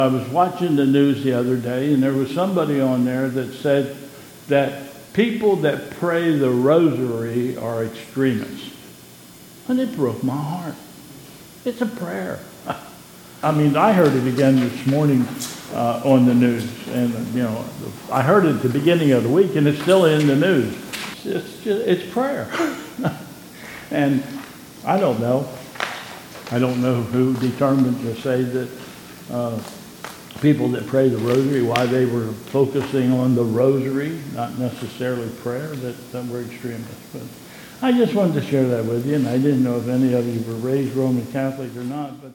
2022 Bethel Covid Time Service
Announcements